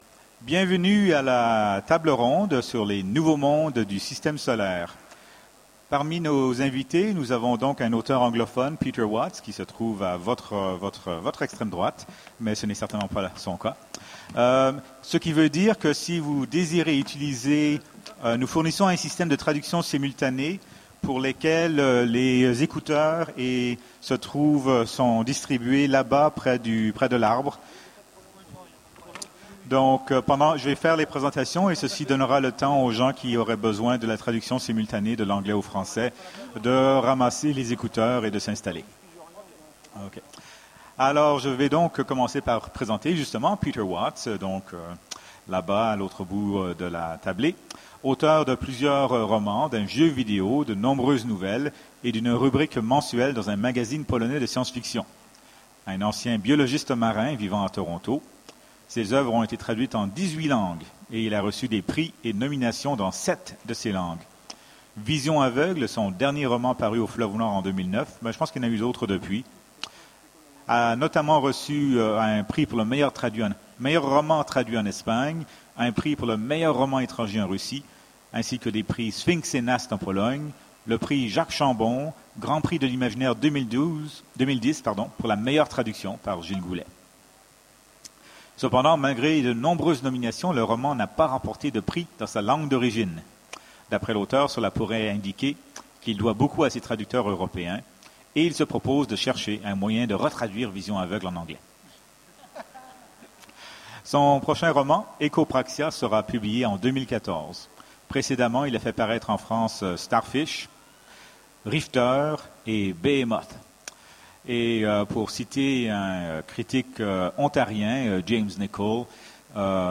Utopiales 13 : Conférence Les nouveaux mondes du système solaire